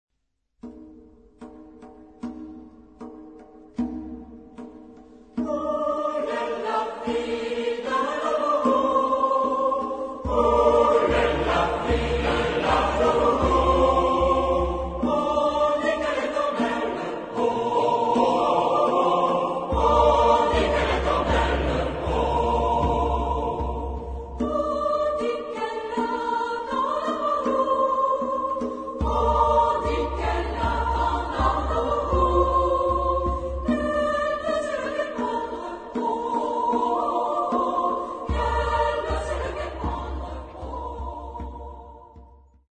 Genre-Style-Form: Popular ; Secular
Mood of the piece: simple
Type of Choir: SMA OR TBarB  (3 equal voices )
Soloist(s): 1 au choix  (1 soloist(s))
Tonality: B flat major
Origin: Vendée (F)